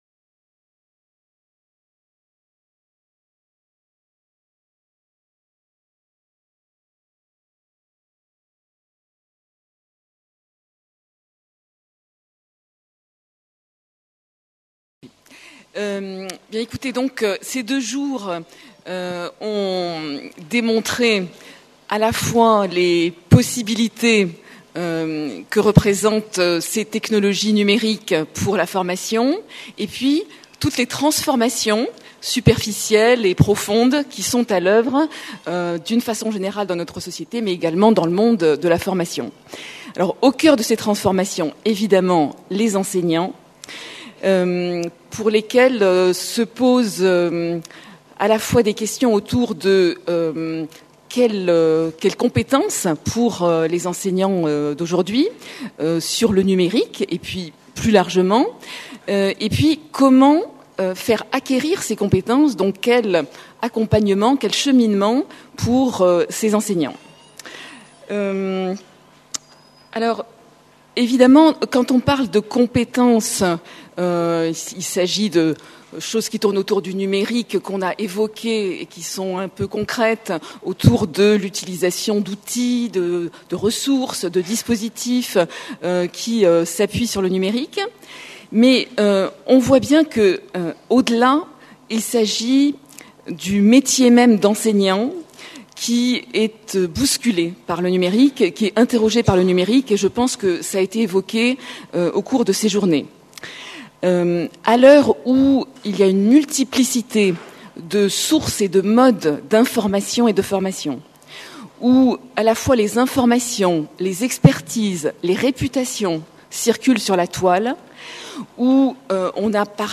PREMIER SÉMINAIRE INTERNATIONAL SANKORÉ DE RECHERCHE UNIVERSITAIRE SUR LA PÉDAGOGIE NUMÉRIQUE